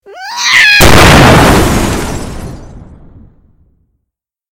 Cat Meme Sound Effect Free Download
Cat Meme